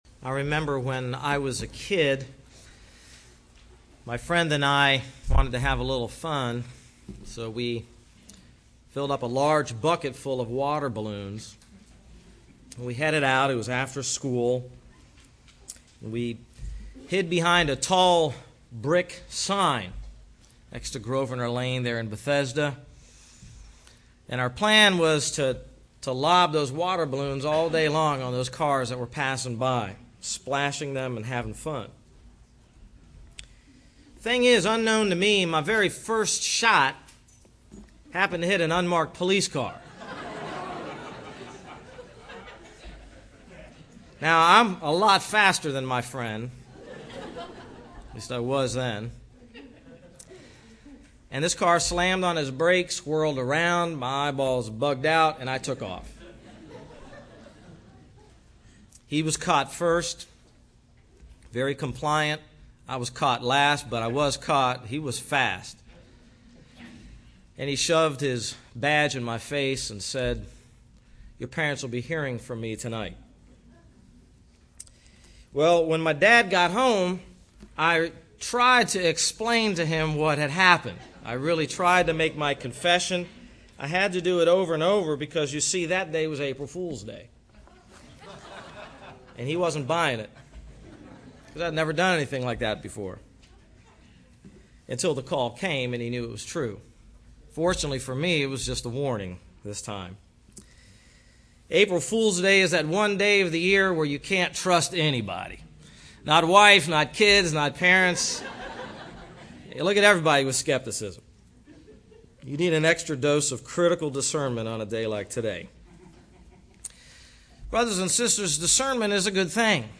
Recent Sermons - Hope Bible Church
Category: Sunday Service